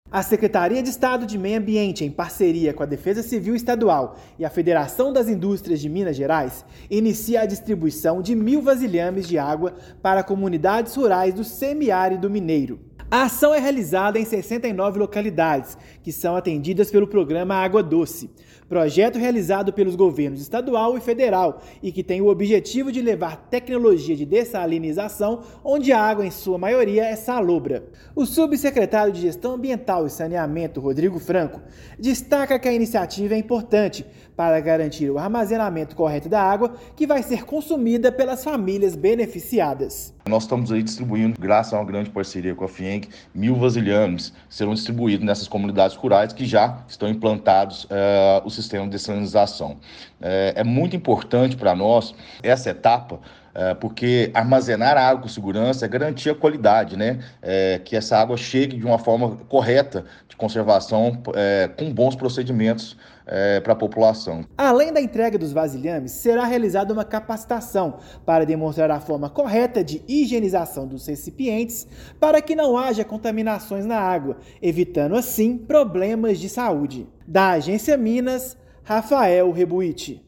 Previsão é a de que as intervenções do Programa Água Doce sejam feitas em 69 comunidades do estado, levando água de qualidade a 28 mil pessoas. Ouça matéria de rádio.